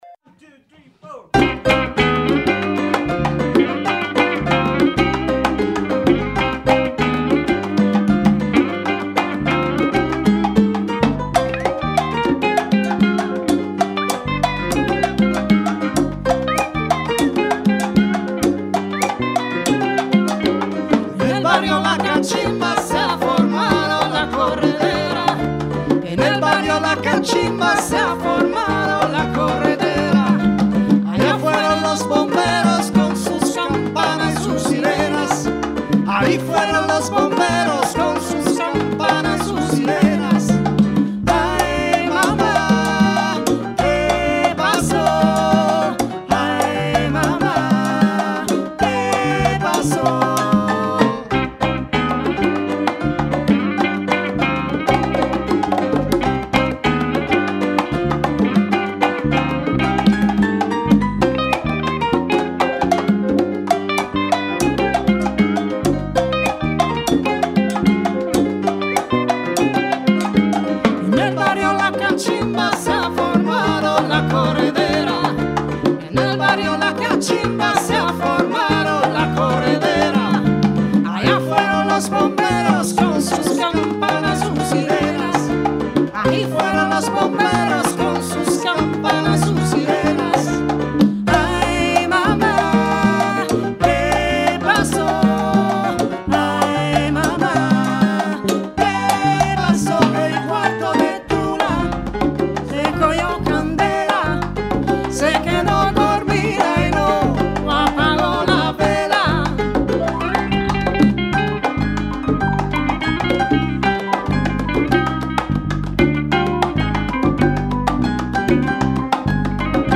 una rassegna musicale dedicata alla canzone d’autore